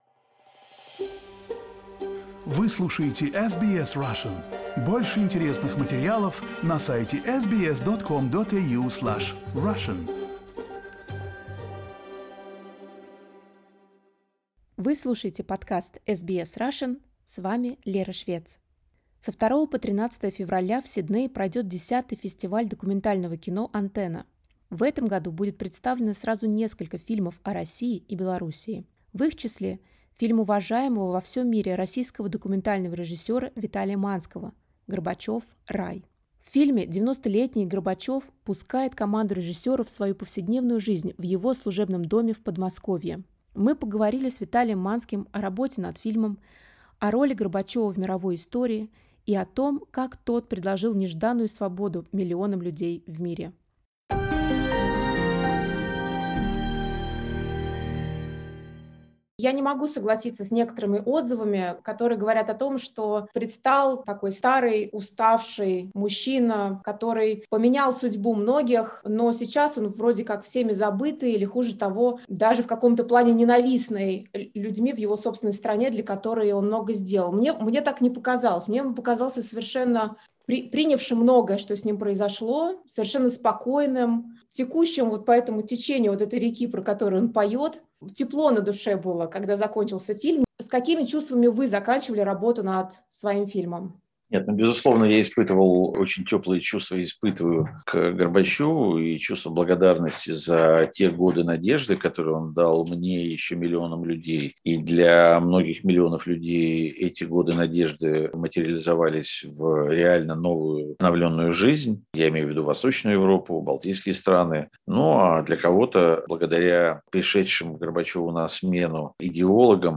Режиссер документального кино Виталий Манский рассказал SBS Russian о своем фильме «Горбачев.Рай». Фильм будет представлен в рамках фестиваля документального кино Antenna в Сиднее в начале февраля.